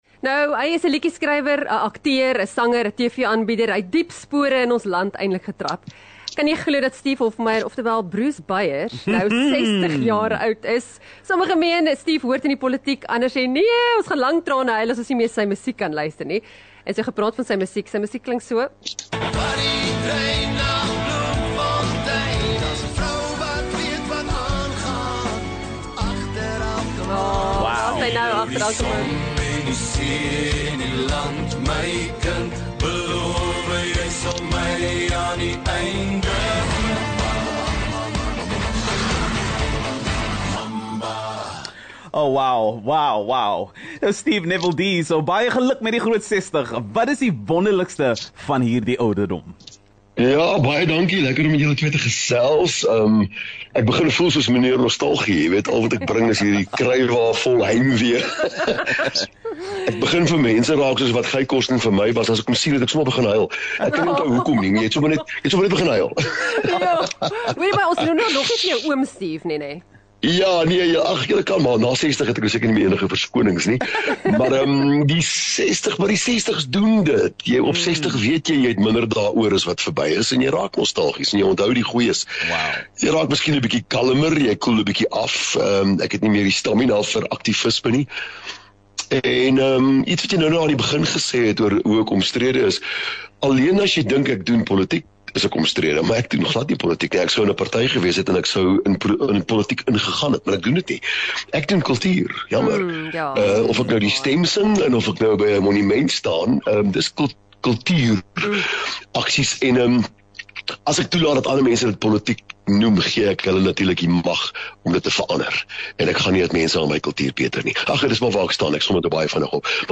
4 Sep Die Real Brekfis gesels met legende Steve Hofmeyer